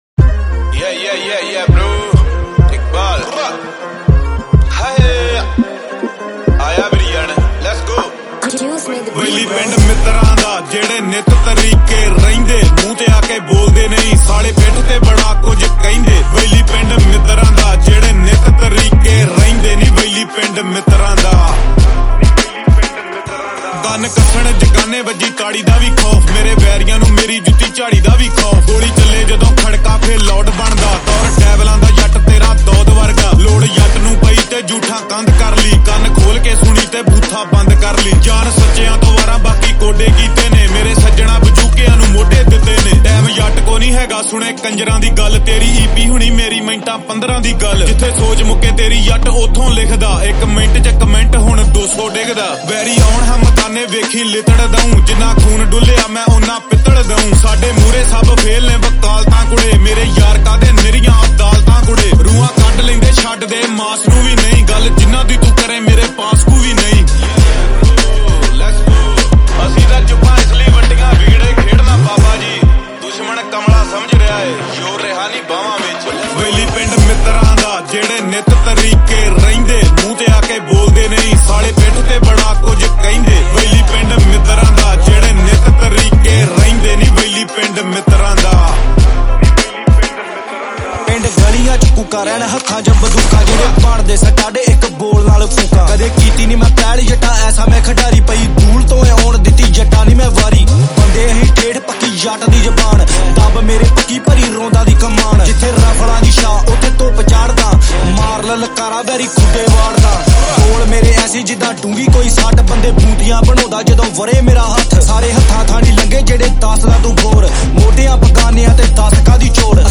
Category: Punjabi Singles